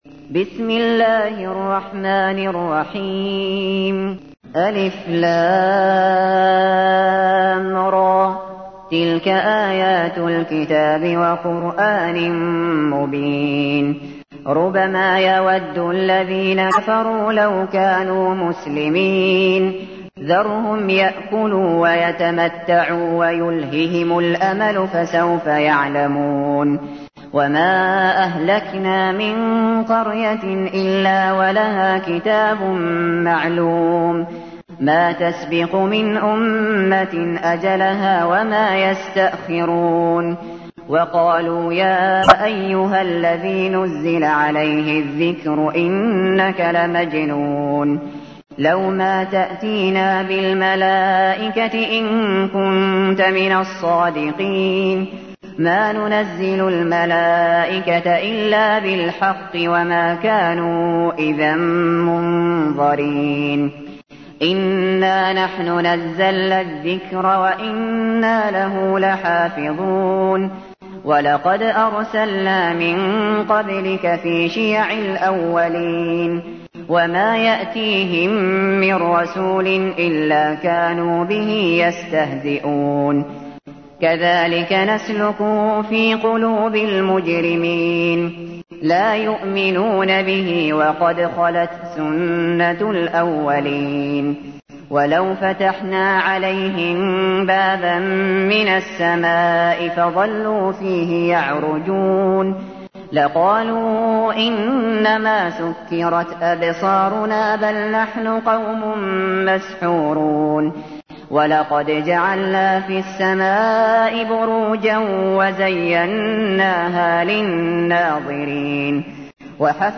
تحميل : 15. سورة الحجر / القارئ الشاطري / القرآن الكريم / موقع يا حسين